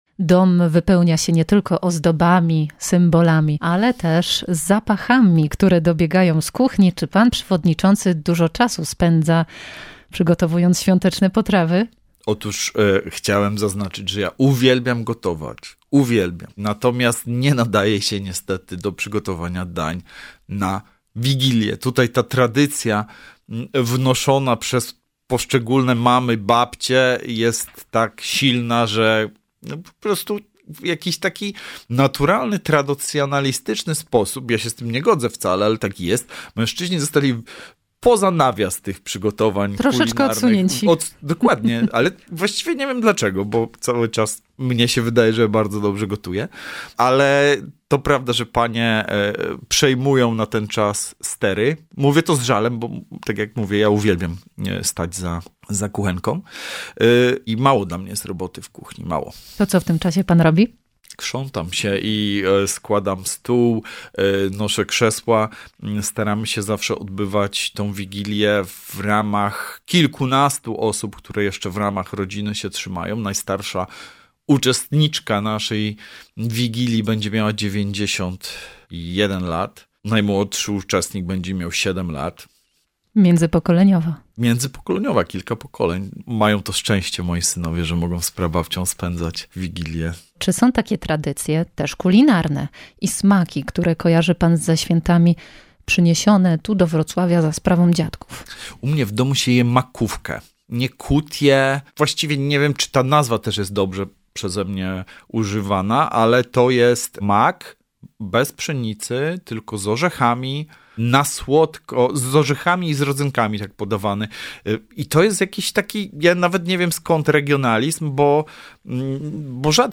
Przystrojona z dziećmi choinka, zbierane przez lata dekoracje, szopka bożonarodzeniowa przywieziona z Palermo czy w końcu czas krzątania się w kuchni przed Wigilią i spotkania przy wspólnym stole. Przewodniczący Rady Miejskiej Wrocławia, Sergiusz Kmiecik, dzieli się tym, jak spędza święta Bożego Narodzenia z rodziną.